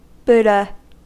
Ääntäminen
Vaihtoehtoiset kirjoitusmuodot Buddha Ääntäminen US Haettu sana löytyi näillä lähdekielillä: englanti Määritelmät Substantiivit An enlightened human being.